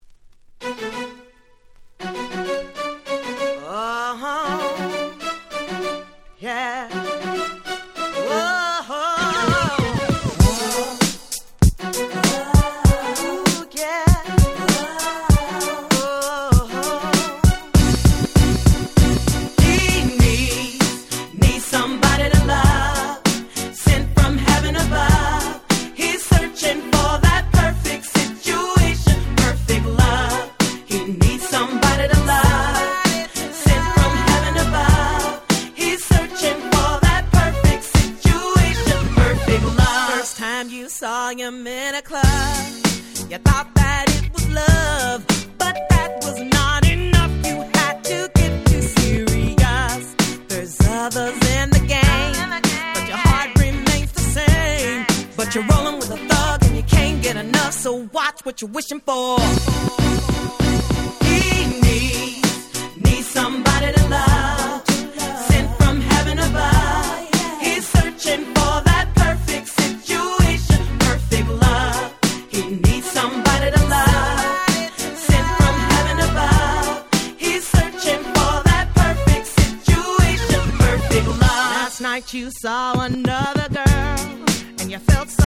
90's 00's R&B キャッチー系 Dance Pop ダンスポップ